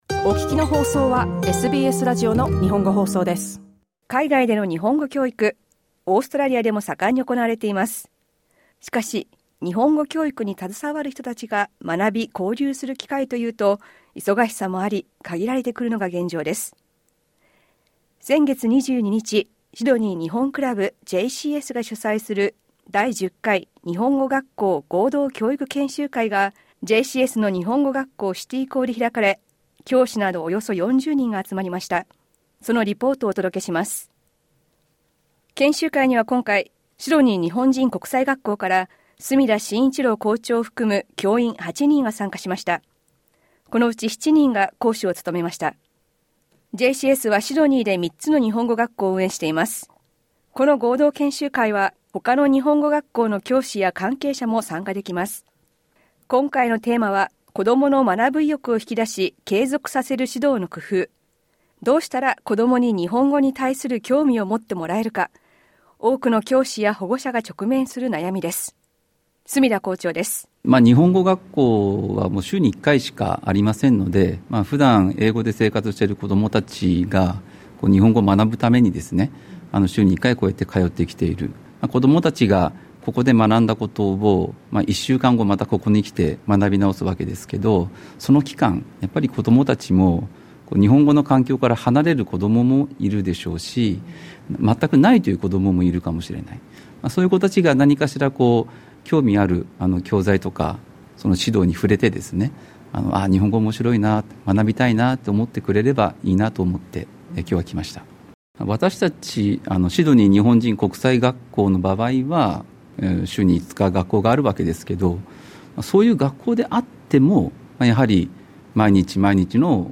そのリポートをお届けします。